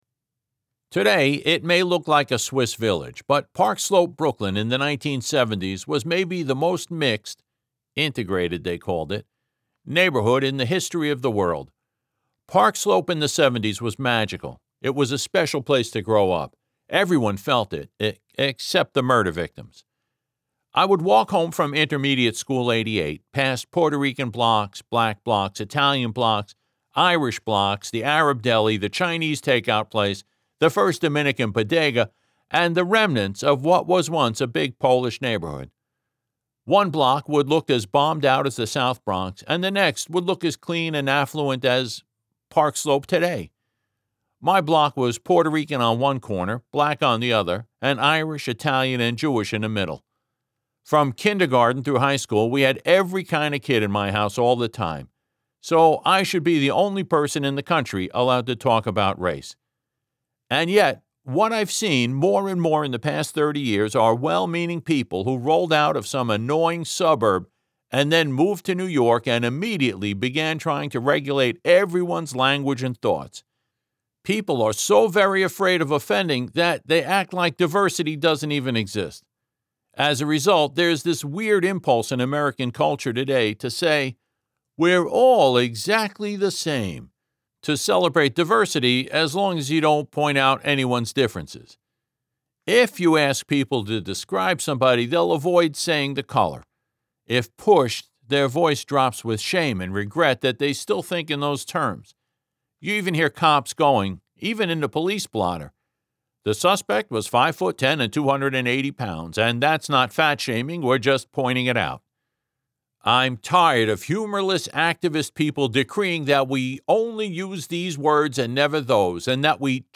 For your dining and dancing pleasure, some samples of my lovely, sometimes rough and growly, voice-
demo-fict-parkslope.mp3